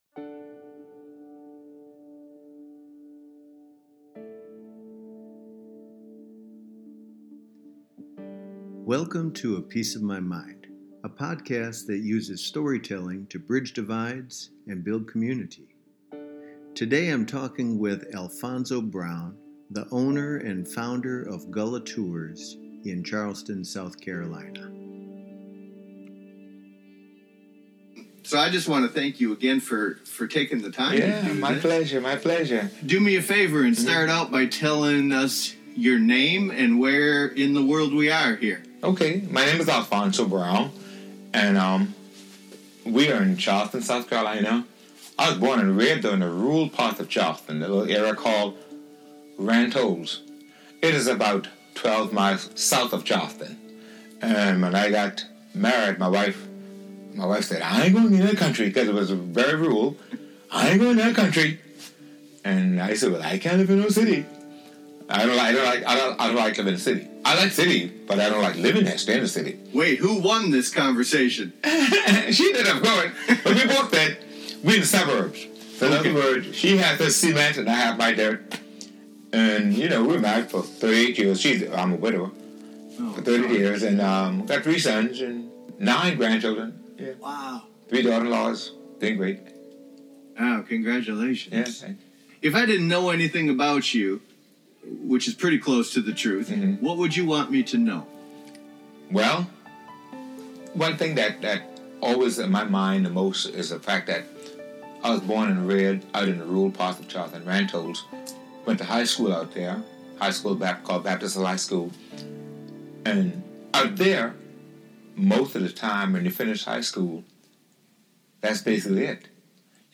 “It’s not bad English,” he said.